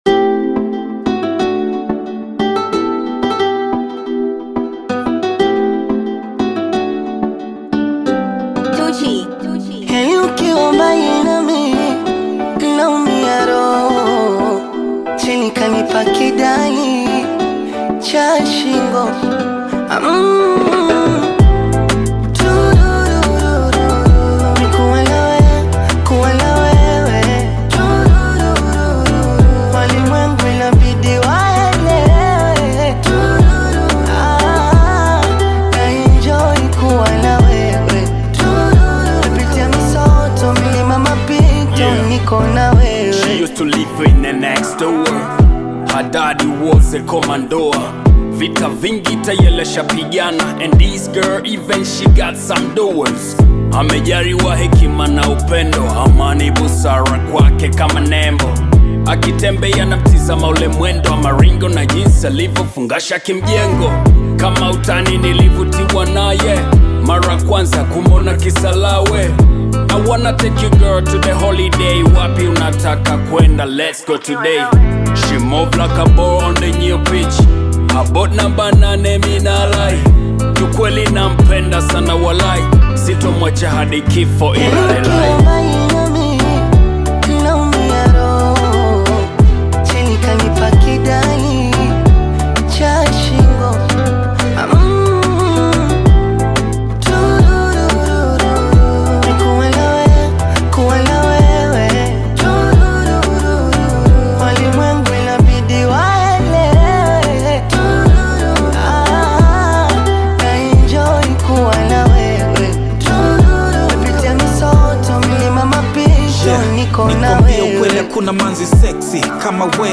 Tanzanian bongo flava
African Music